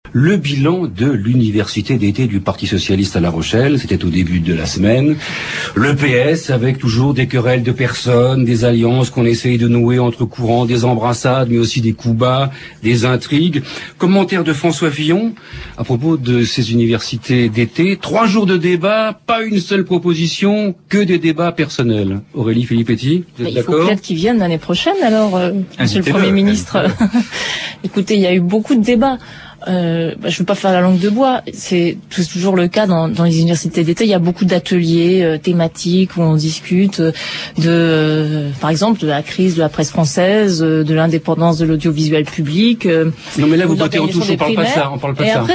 France Inter, Le téléphone sonne : « Les polémiques de la semaine ». Le 5 septembre 2008, Aurélie Filippetti, députée PS de Moselle, porte-parole du groupe socialiste à l’Assemblée nationale est à l’antenne.